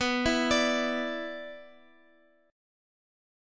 B-Minor Double Flat 5th-B-x,x,x,6,7,9-8-down-Guitar-Open F.m4a